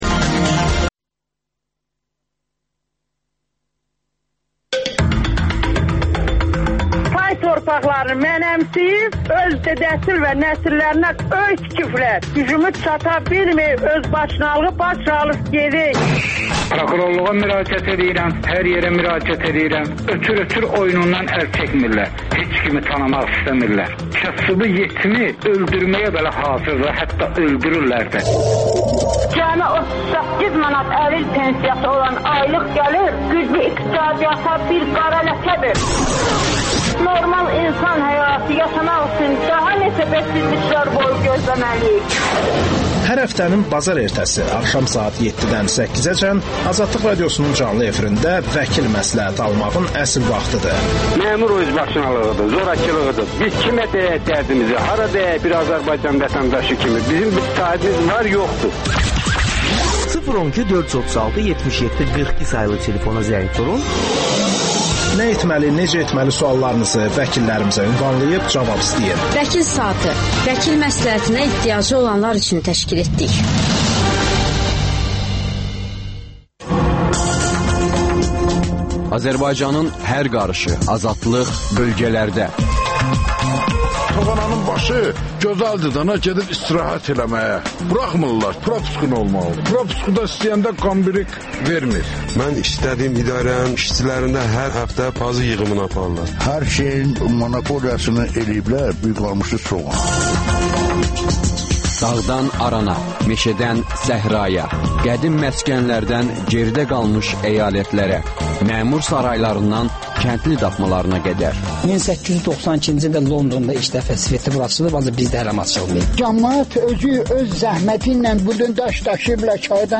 AzadlıqRadiosunun müxbirləri ölkə və dünyadakı bu və başqa olaylardan canlı efirdə söz açırlar.